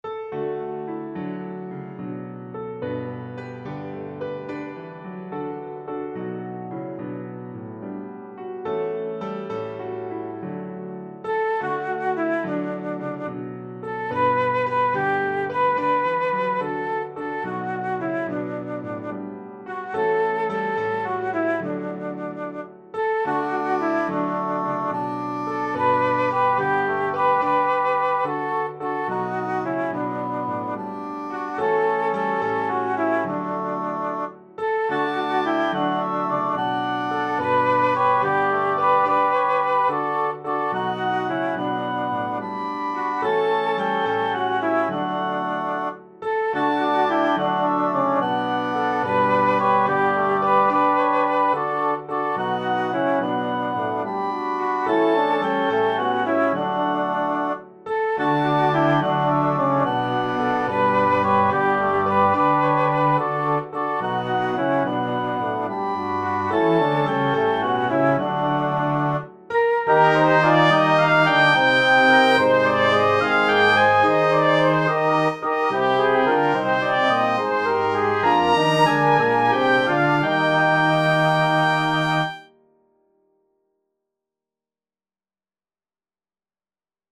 O-Holy-Night-6-Verses-Piano-and-Small-Ensemble.mp3